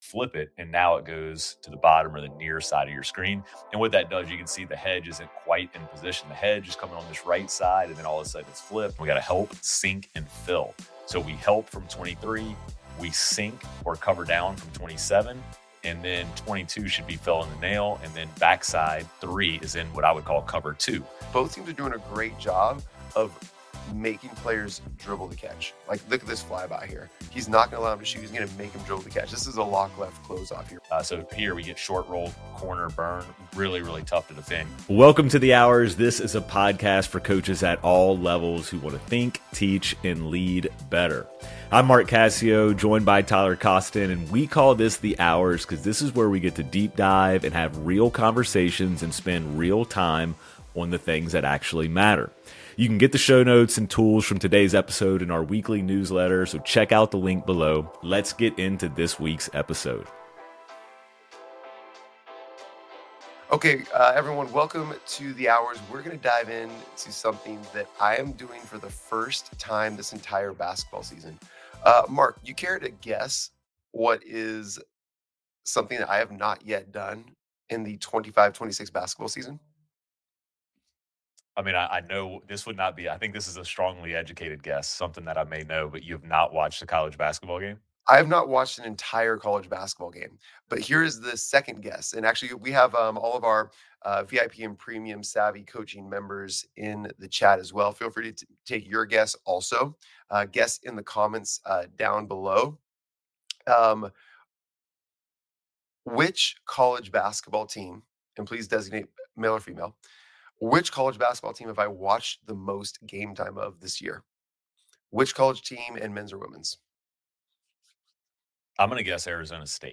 We pulled up Iowa State vs. Houston on Synergy and broke it down live — no prep, no pre-watch, just two coaches watching film and calling what they see.